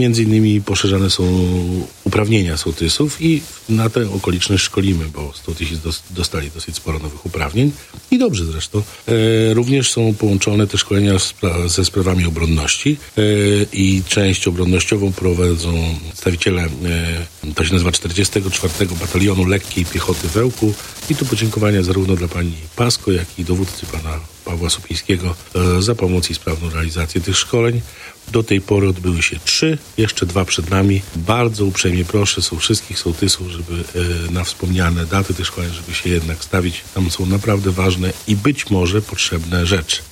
Mówi w Radiu 5 Tomasz Osewski, wójt gminy Ełk.